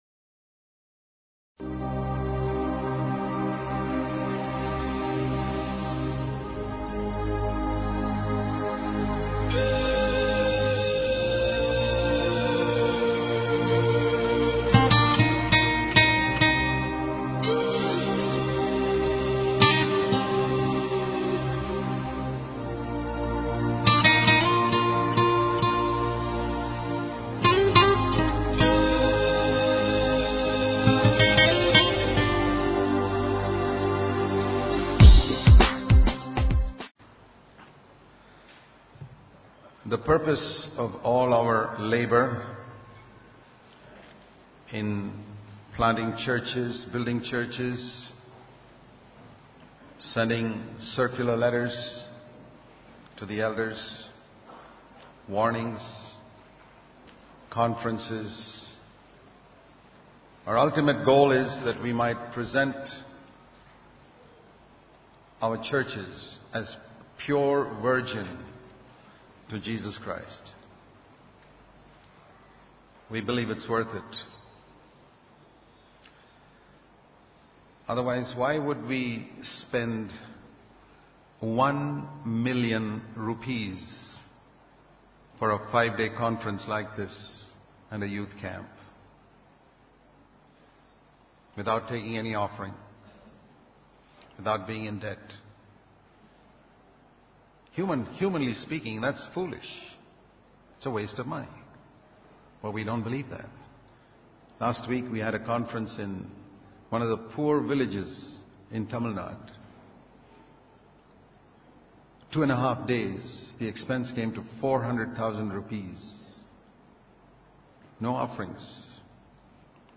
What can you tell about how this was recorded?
Presenting The Church As A Virgin To Christ New Wine In New Wineskins The live streamed messages spoken during the 2011 Bangalore Conference.